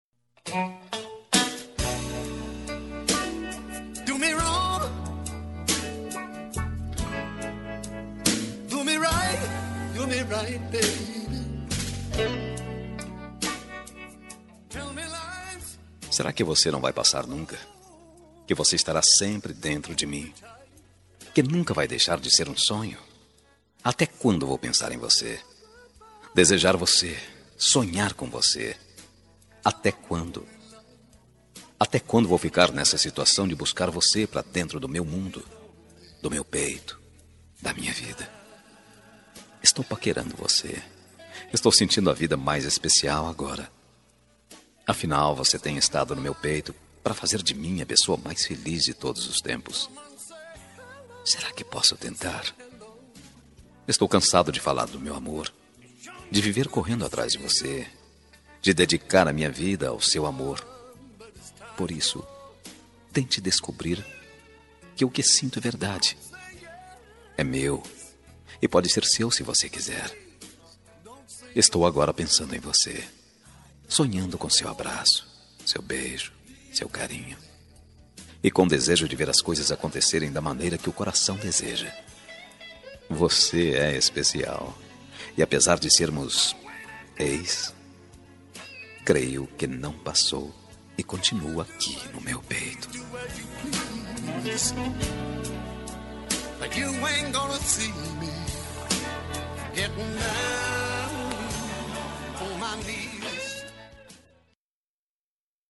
Telemensagem Romântica para Ex – Voz Masculina – Cód: 8089